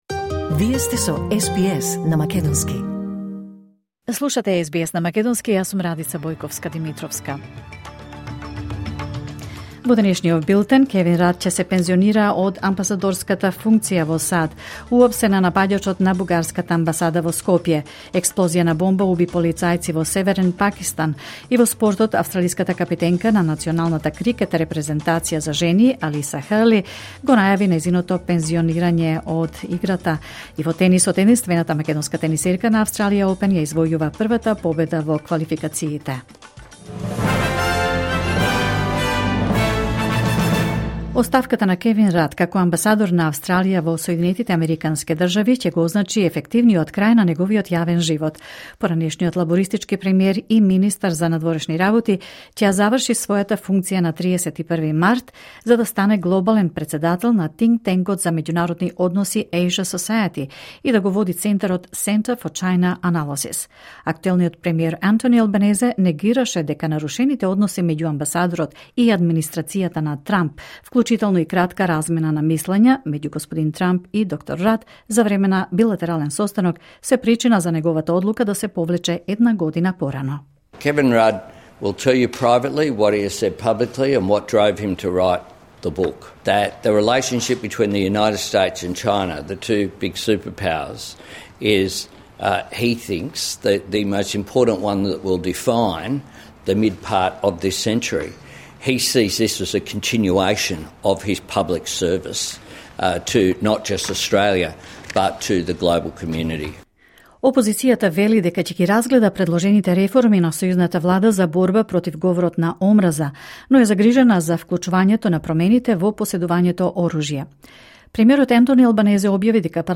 Вести на СБС на македонски 13 јануари 2026